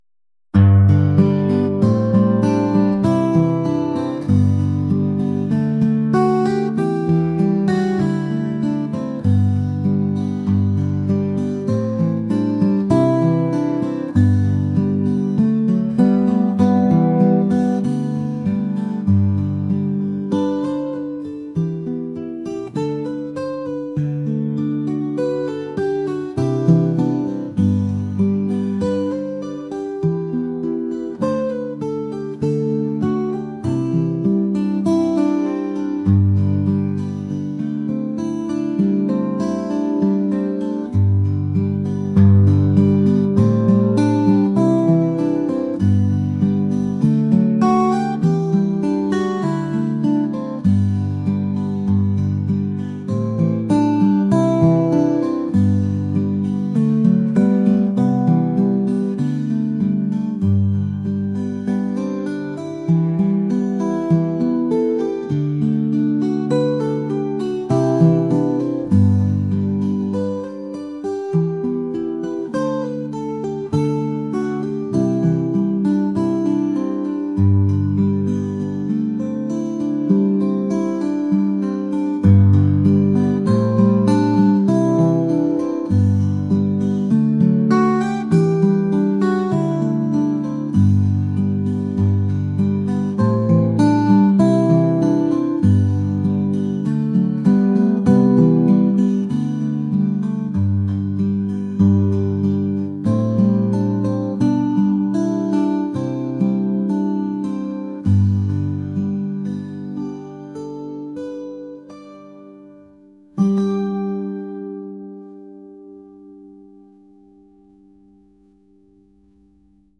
acoustic | folk | laid-back